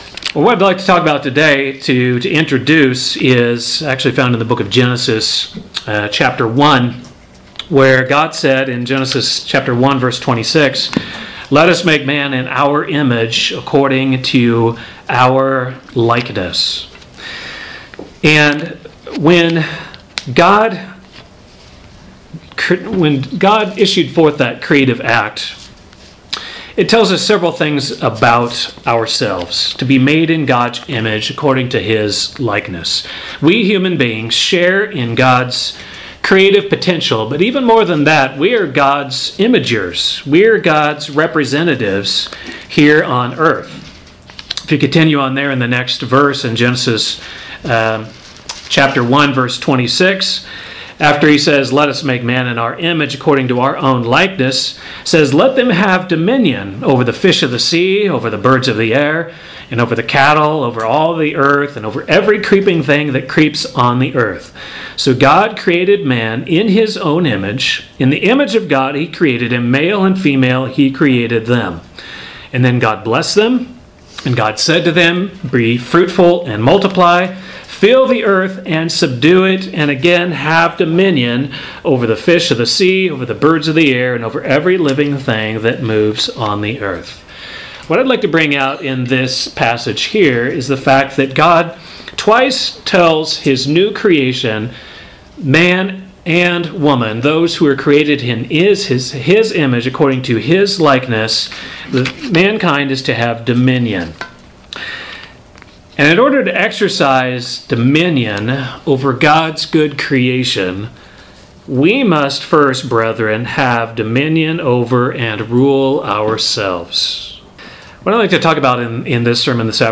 Given in Kansas City, KS